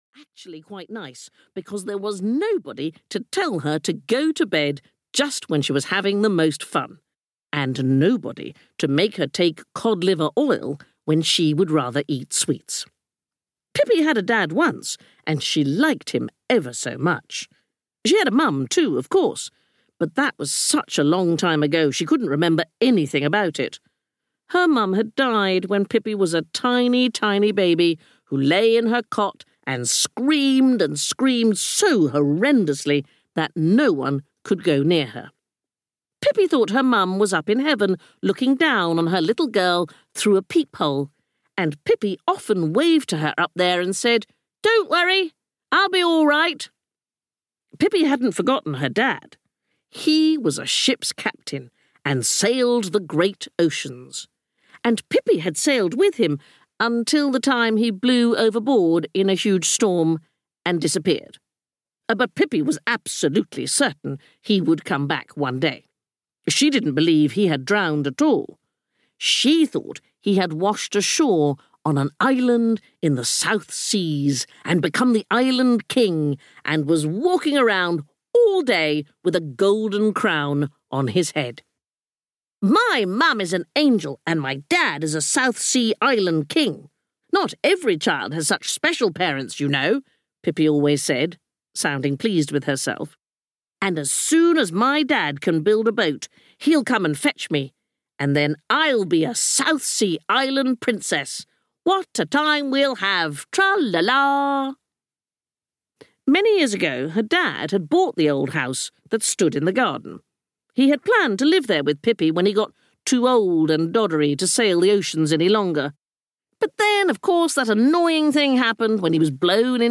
Pippi Longstocking (EN) audiokniha
Ukázka z knihy
• InterpretSandi Toksvig